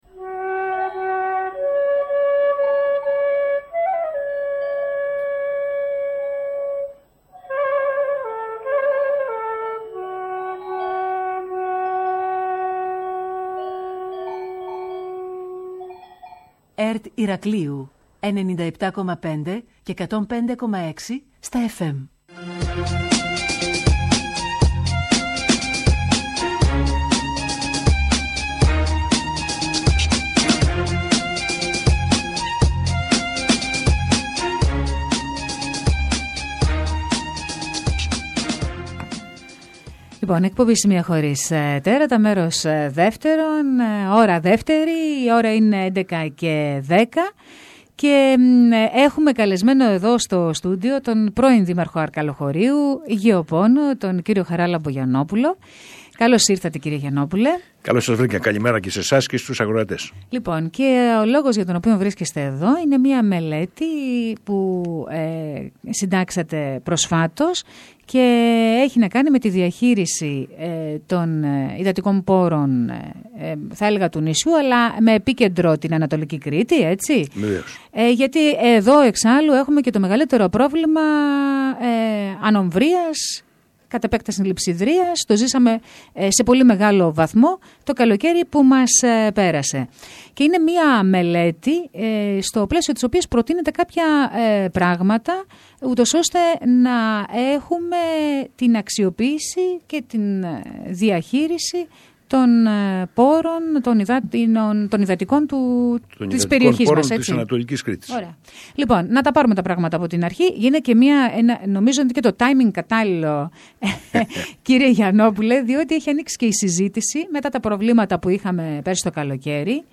Τις προτεινόμενες παρεμβάσεις, καθώς και τον τρόπο με τον οποίο αυτές μπορούν να λειτουργήσουν συνολικά, περιέγραψε ο κ. Γιαννόπουλος σήμερα μιλώντας στην ΕΡΤ Ηρακλείου.